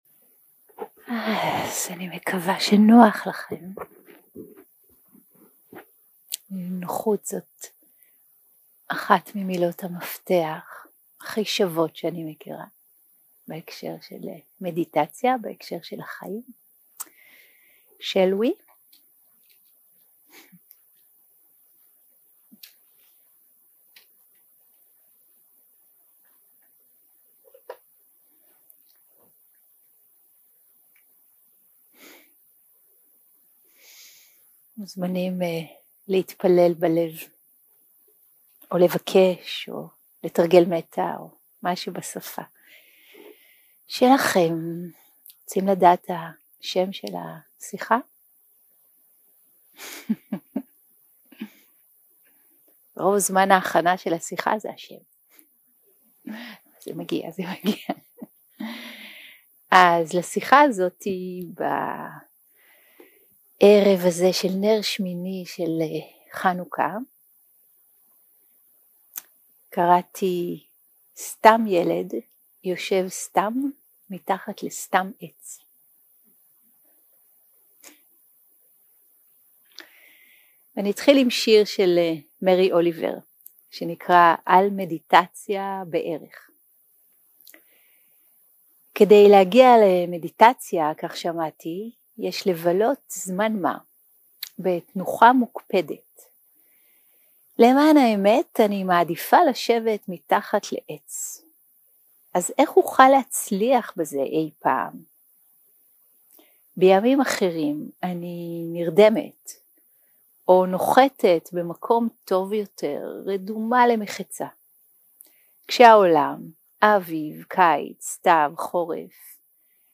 סוג ההקלטה: שיחות דהרמה שפת ההקלטה